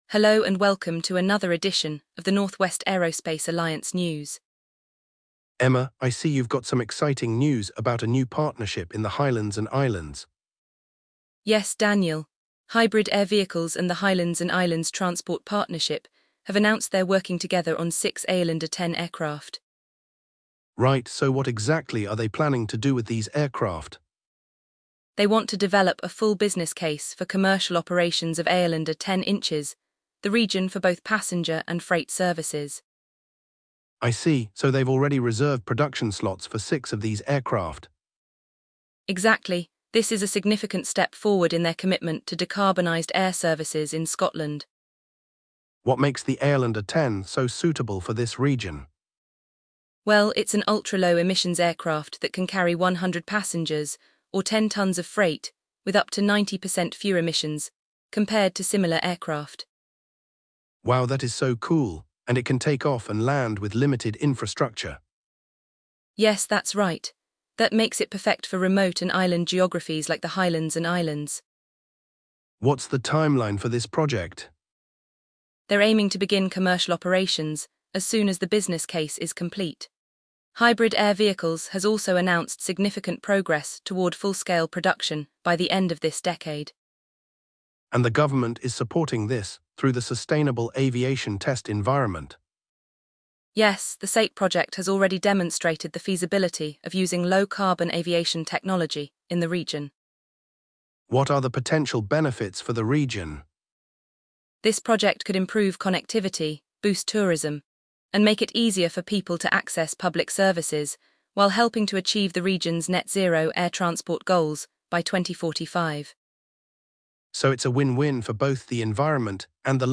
The duo discusses the benefits of this project for the region, including improved connectivity, boosted tourism, and progress toward net-zero air transport goals by 2045.